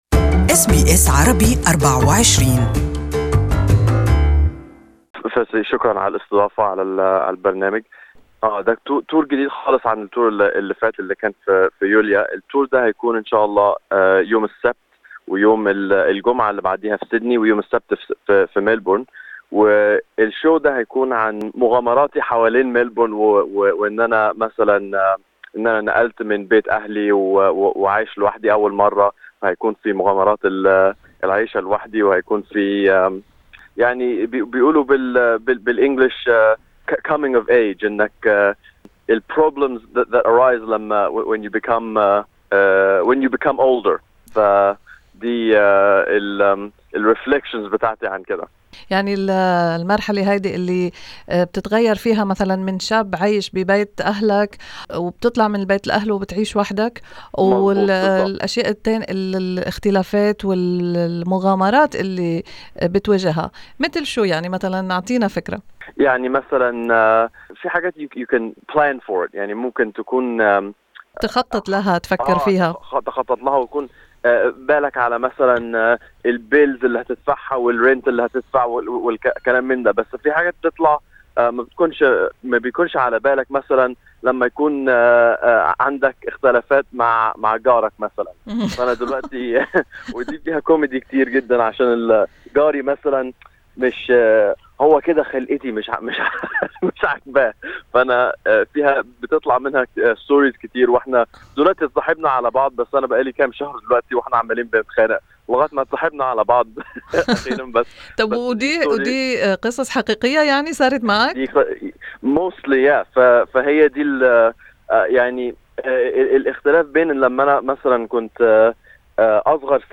Interview is in Arabic Share